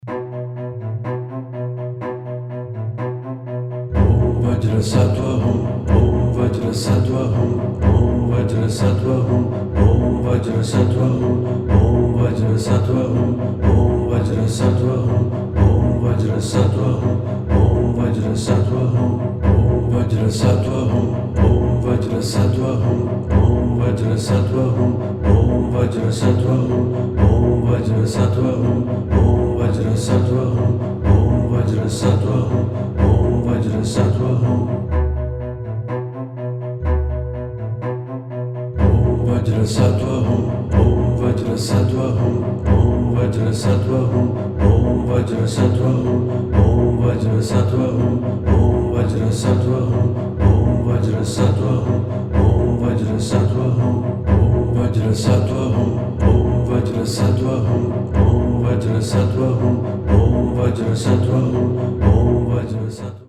OM-Vajrasattva-Hum-Buddha-Chants.mp3